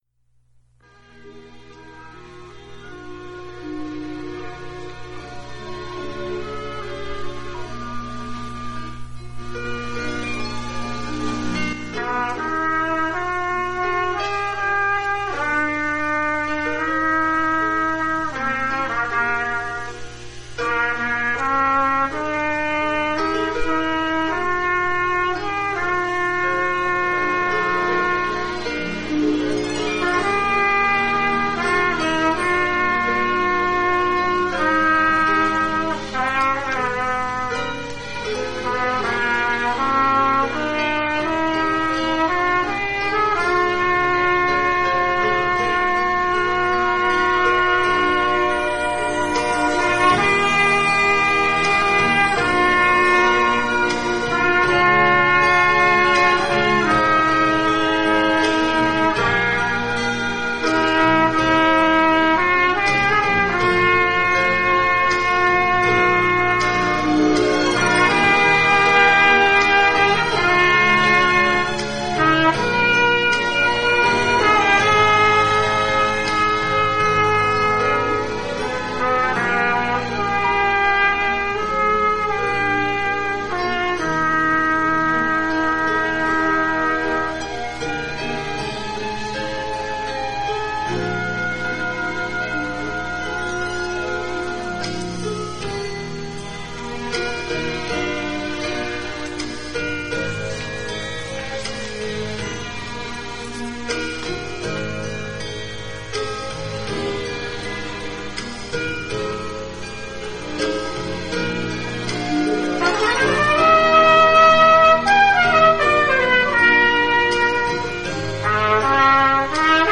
PLAY Written in Red Cantata, March 20, 2005 "Written in Red," words and music by Gordon Jensen. Trumpet solo
Alturas Baptist Church Choir.